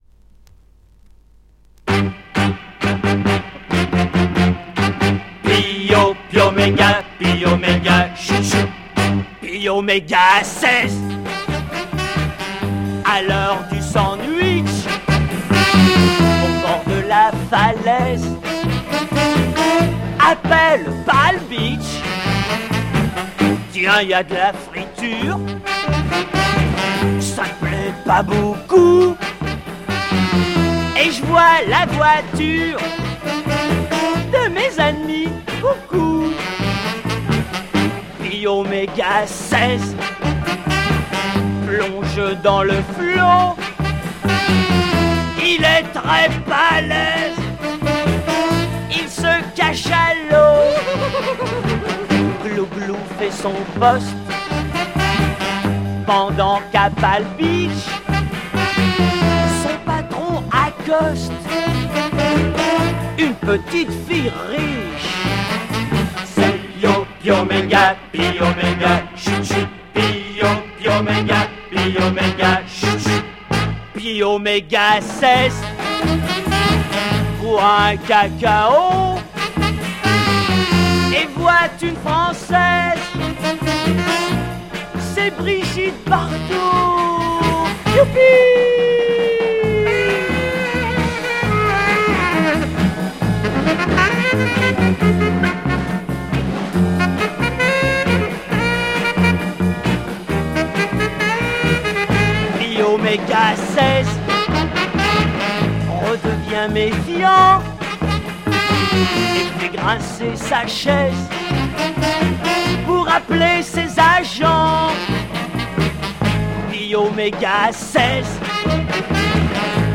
Obscure French Private R&B Mod Garage punk lo-fi EP
quite lo-fi recording